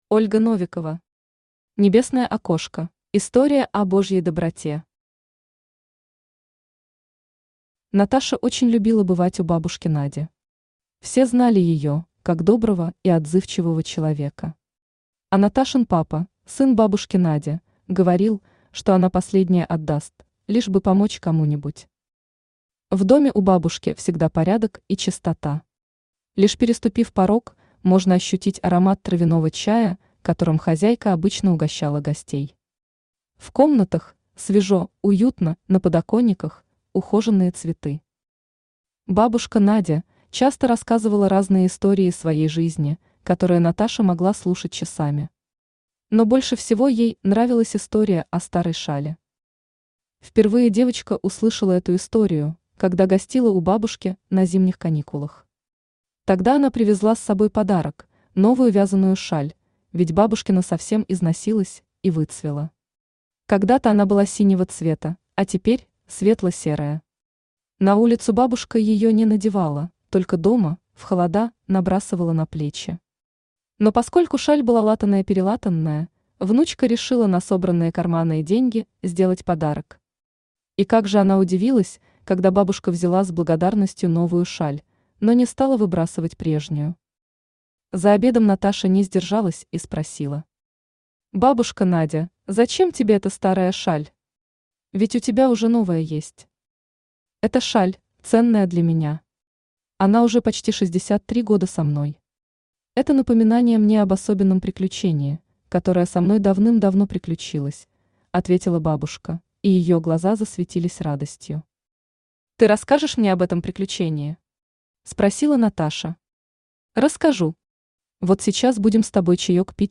Aудиокнига Небесное окошко Автор Ольга Николаевна Новикова Читает аудиокнигу Авточтец ЛитРес. Прослушать и бесплатно скачать фрагмент аудиокниги